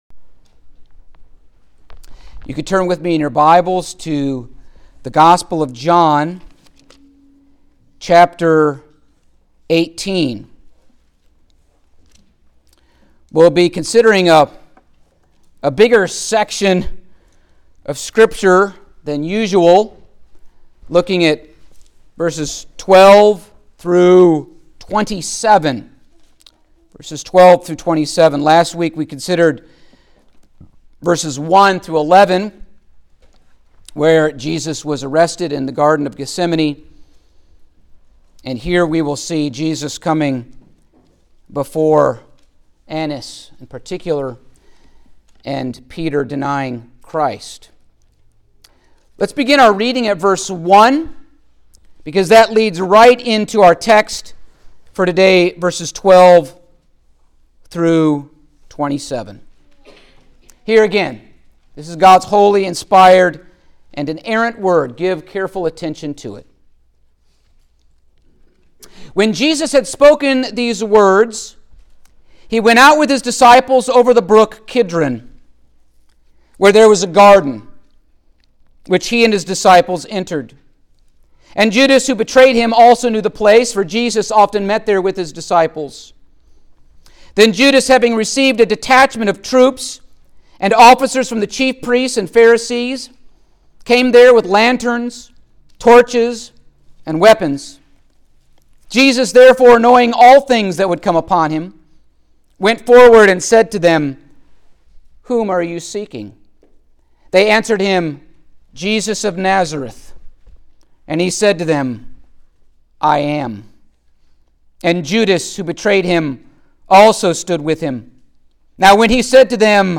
Passage: John 18:12-27 Service Type: Sunday Morning